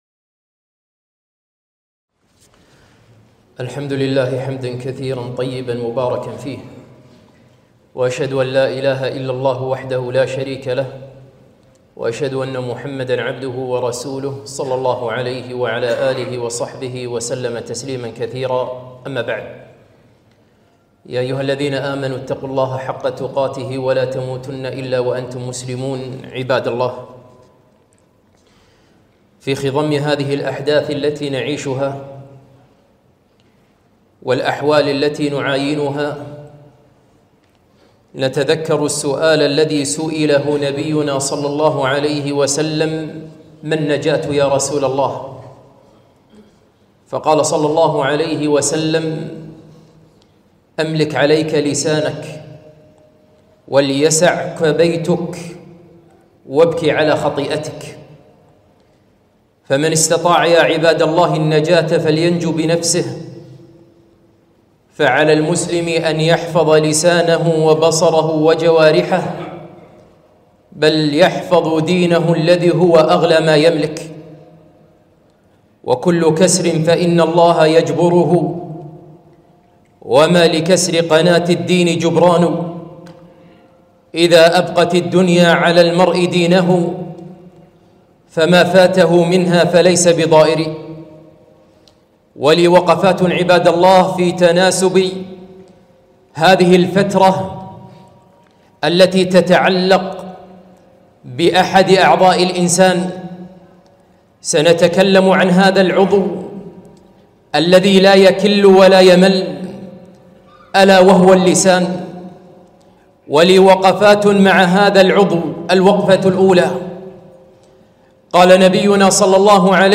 خطبة - وقفات مع اللسان زمن الانتخابات